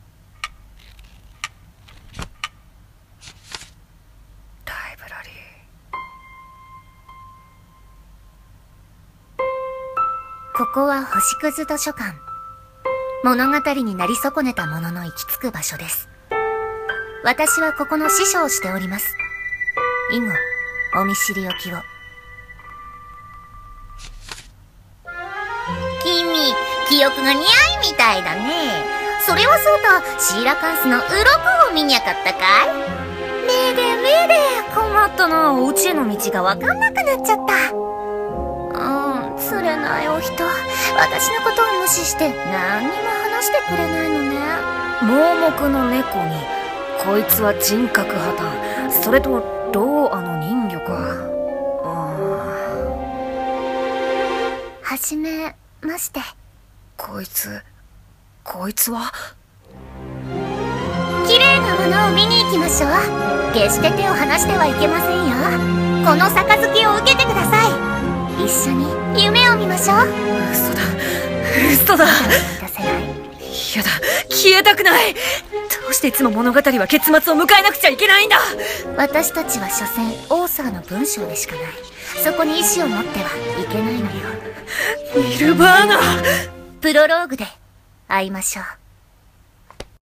CM風声劇「Library」お手本